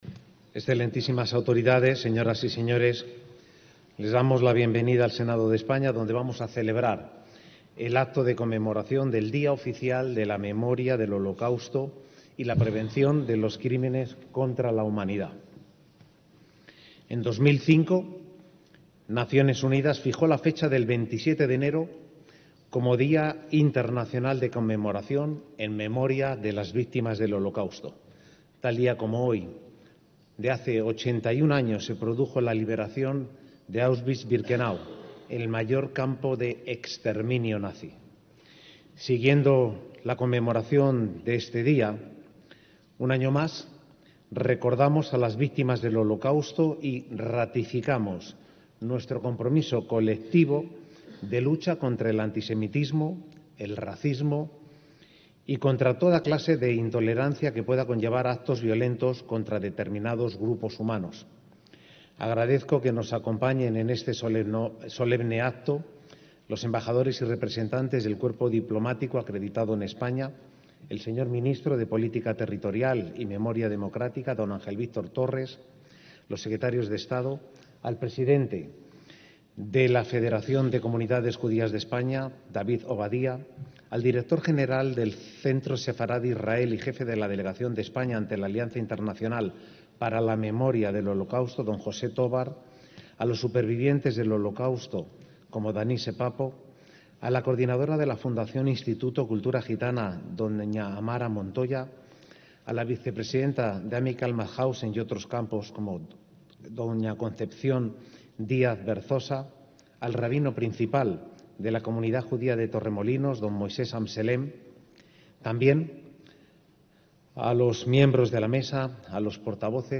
ACTOS EN DIRECTO - Como cada año, se celebró el 27 de enero de 2026 en el Senado de España el acto por el Día Oficial de la memoria del Holocausto y la prevención de los crímenes contra la humanidad.